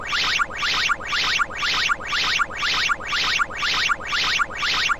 shard_expel.ogg